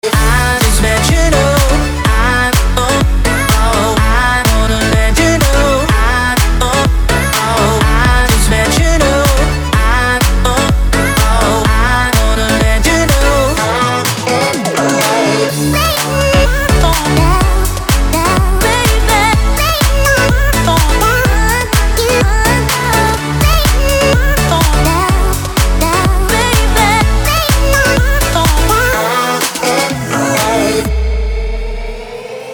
• Качество: 320, Stereo
мужской вокал
dance
Electronic
Dance Pop
club
забавный голос